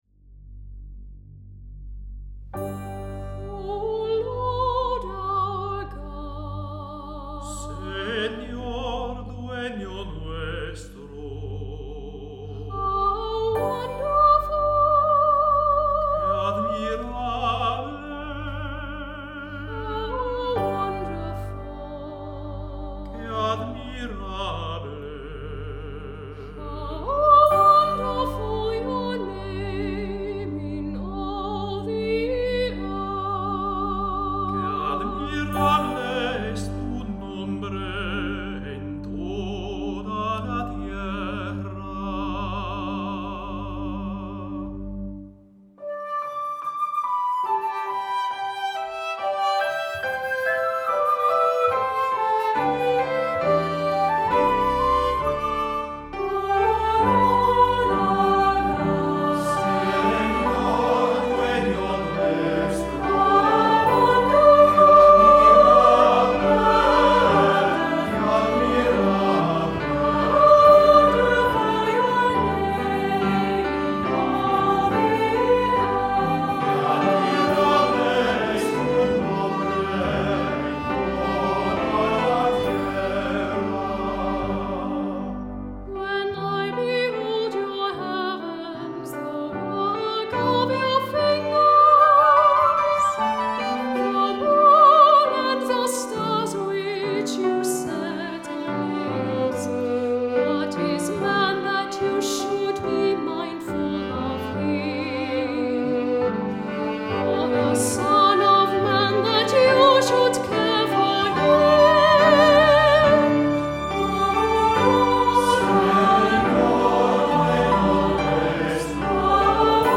Voicing: SATB; Descant; Two cantors; Assembly